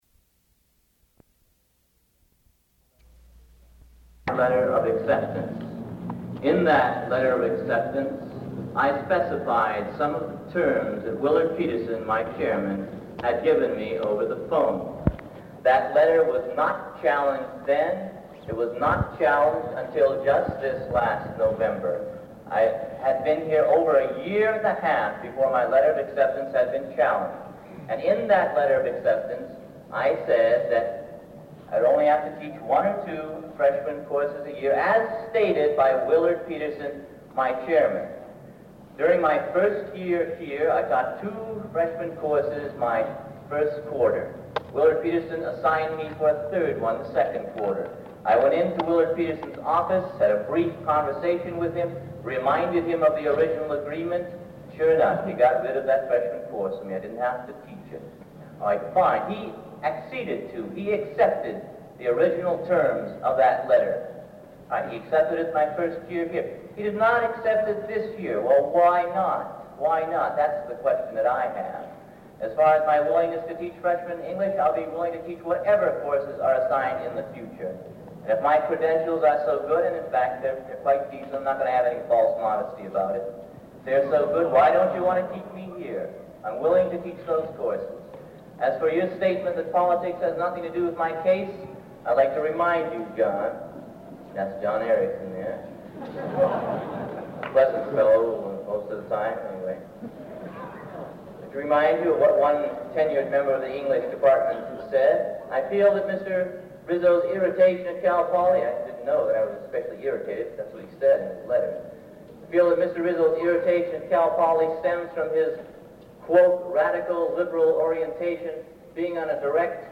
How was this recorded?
[15:00] Small cut between Side A and Side B of tape